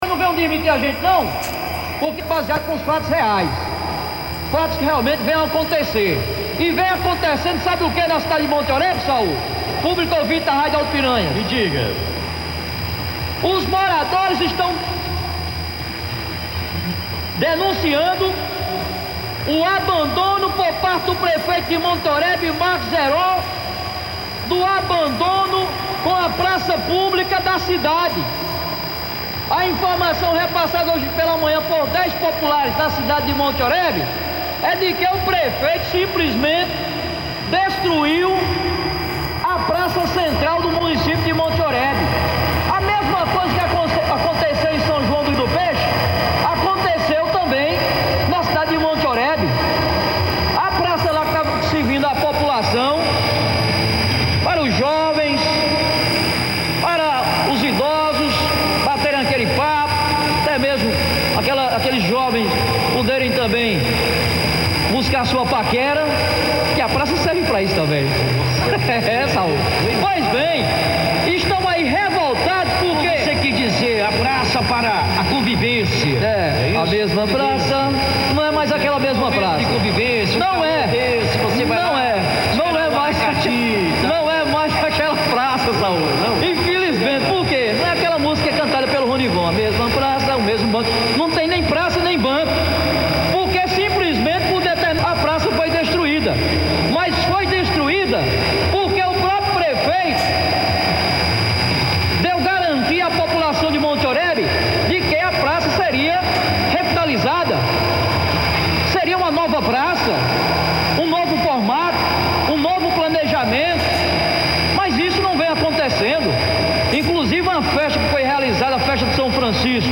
As informações repercutidas aqui pelo Radar Sertanejo foram veiculadas pela Rádio Alto Piranhas da cidade de Cajazeiras.